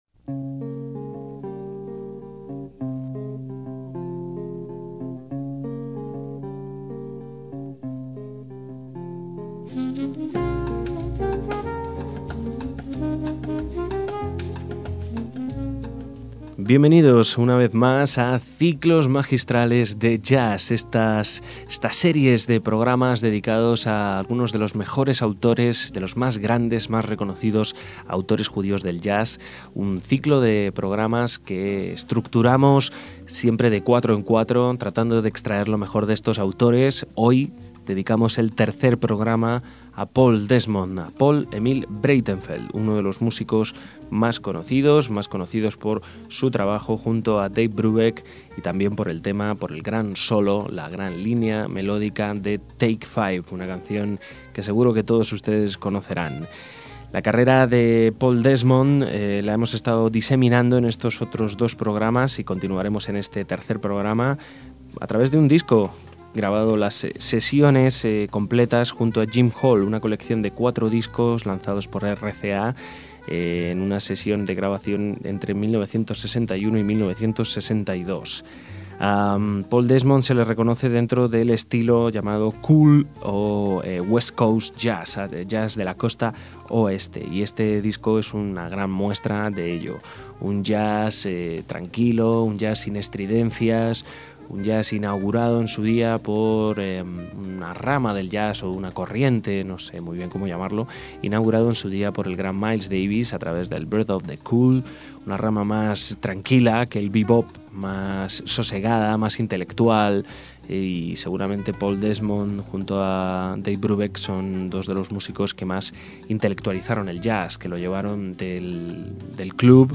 Su forma de tocar era fluida y mucho más suave que otros saxos, con un gran sentido del swing.